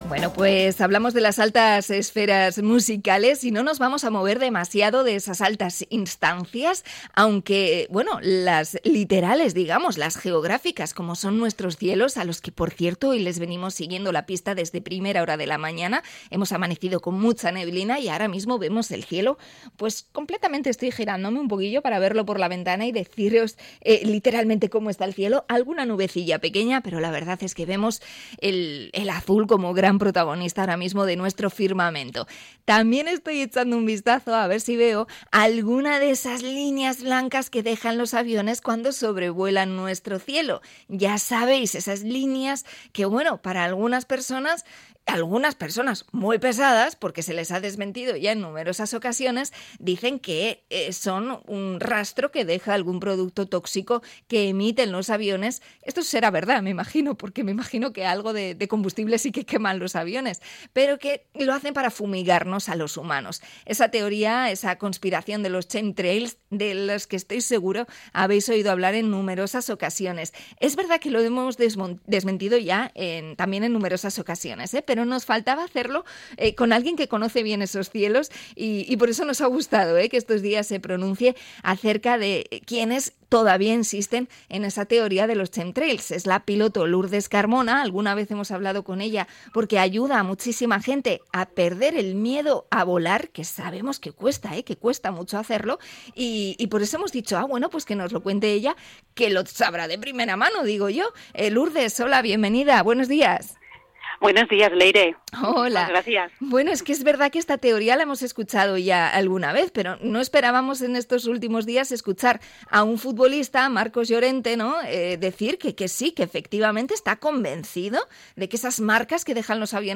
Entrevista a piloto sobre el bulo de los chemtrails
La conversación se ha emitido en el programa EgunON Magazine.